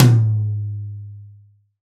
Index of /90_sSampleCDs/AKAI S6000 CD-ROM - Volume 3/Drum_Kit/DRY_KIT2
M-TOM12-1 -S.WAV